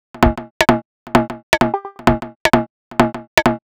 Mode 130bpm.wav